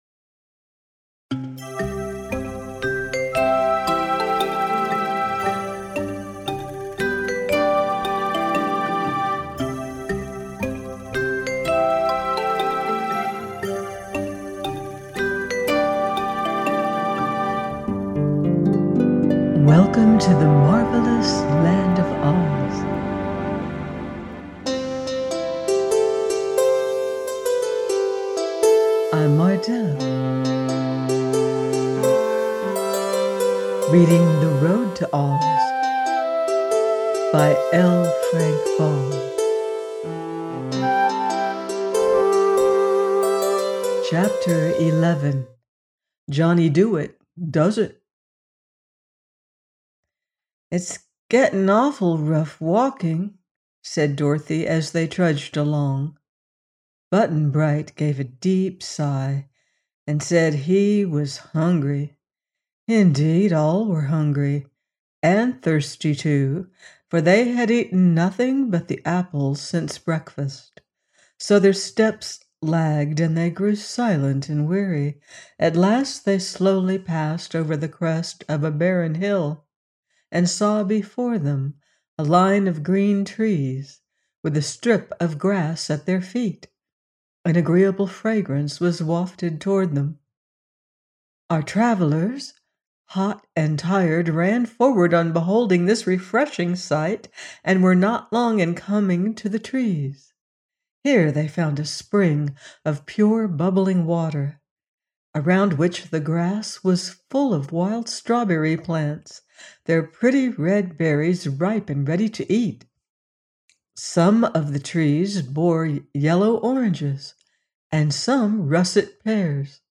The Road To OZ – by L. Frank Baum - audiobook